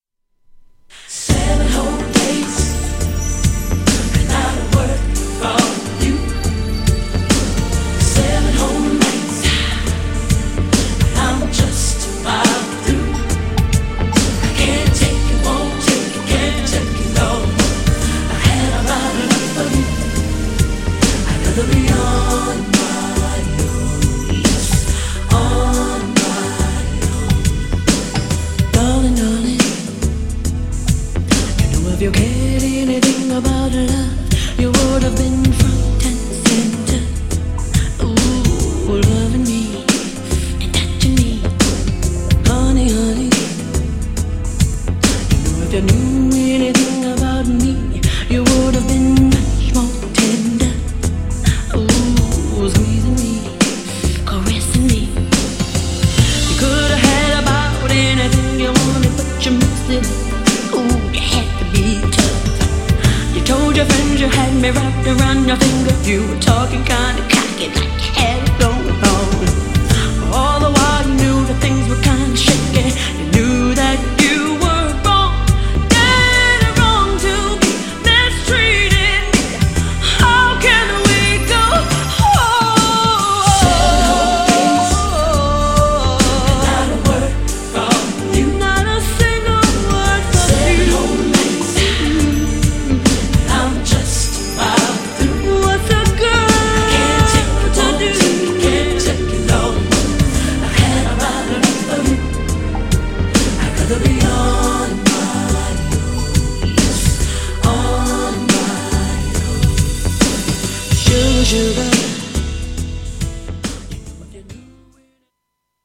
だけど打ち込みが強くてフロア映えも良し。
GENRE R&B
BPM 81〜85BPM